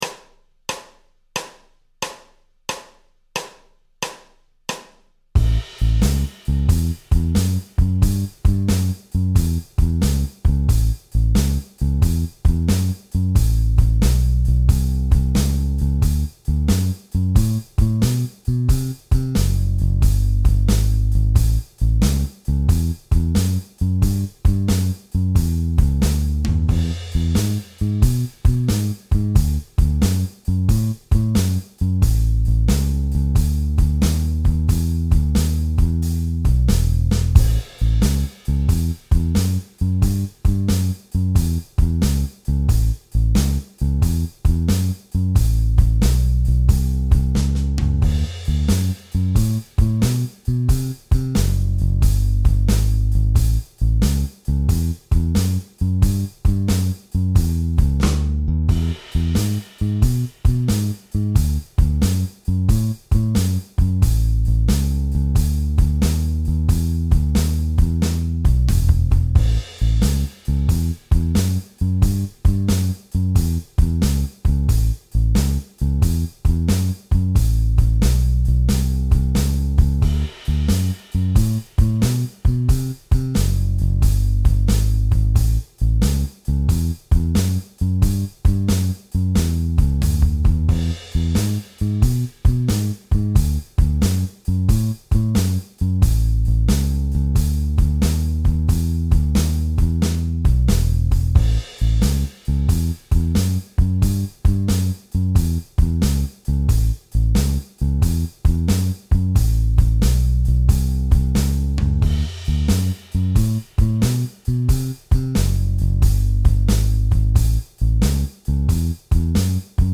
Add a new sound to your blues guitar solos with major pentatonic scales.